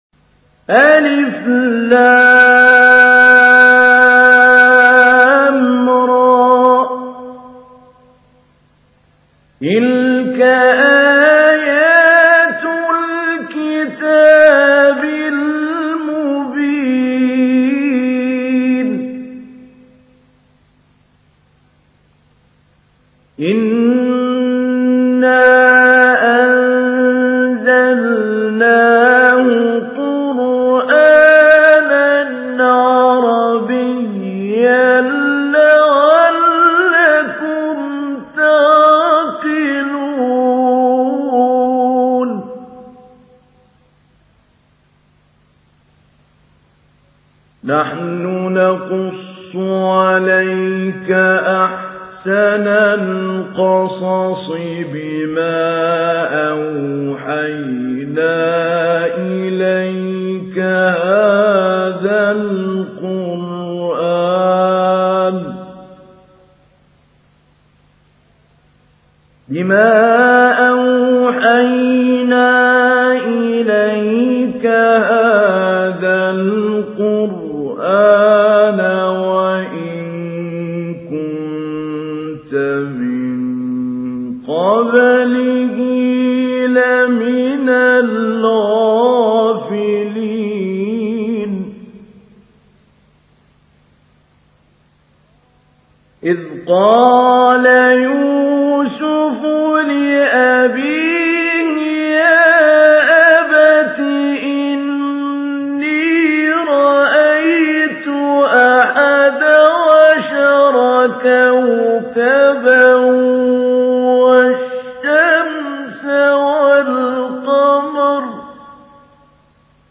تحميل : 12. سورة يوسف / القارئ محمود علي البنا / القرآن الكريم / موقع يا حسين